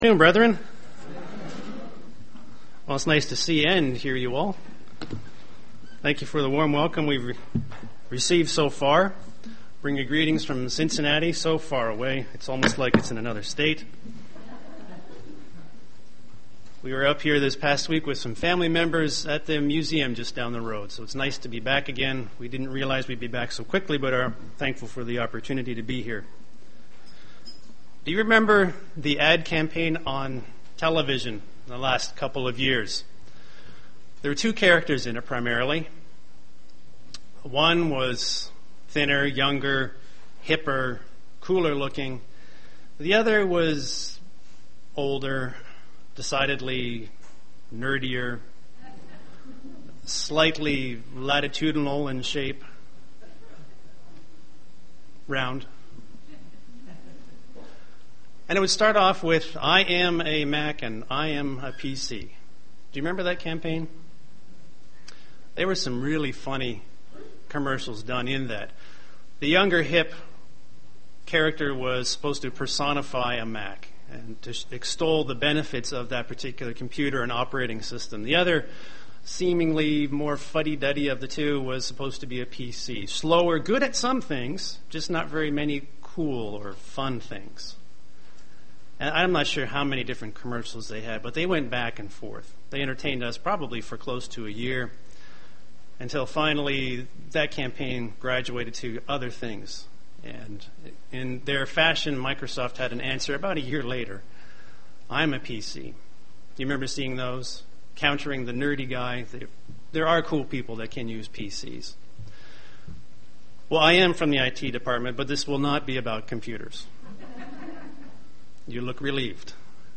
Print Discover the Seven I AMs of Jesus Christ UCG Sermon Studying the bible?